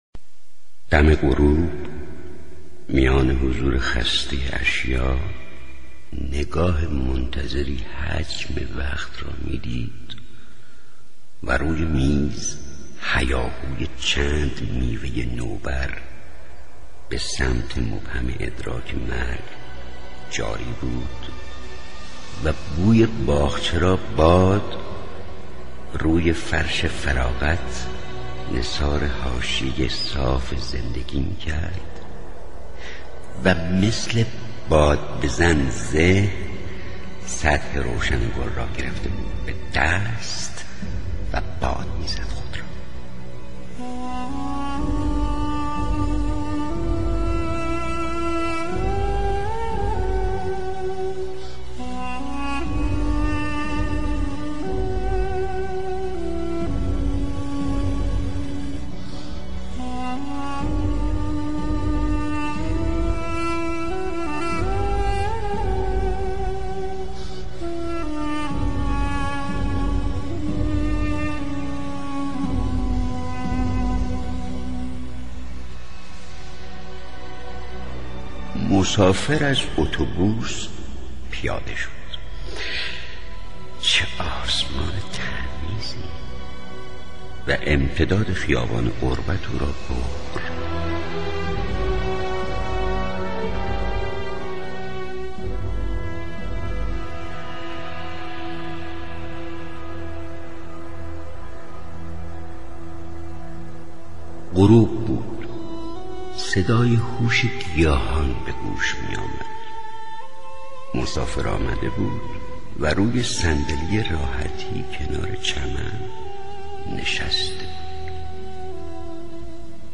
مسافر (با صدای سهراب سپهری)